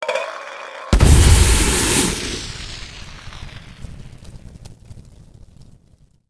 smokegrenade.ogg